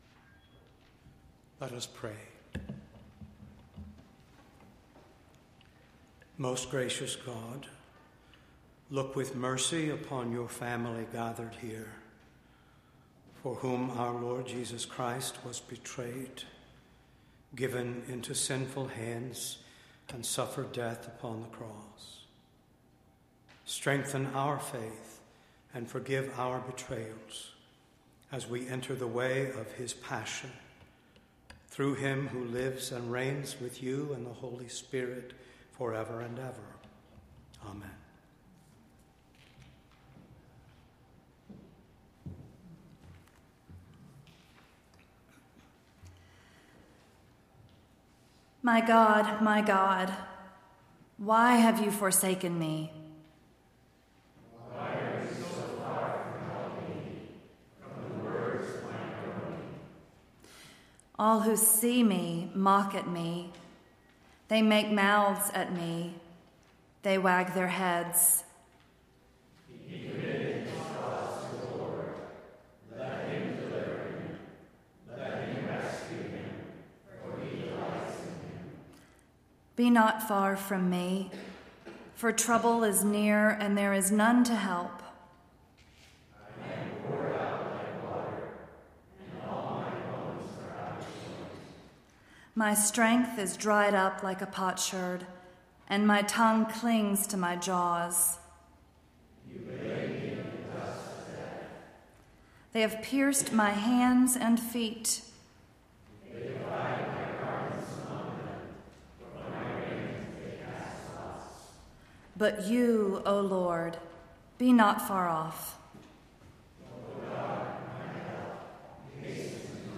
April 19, 2019 – A Service of Tenebrae | First Baptist Church of Ann Arbor
Entire April 19th Service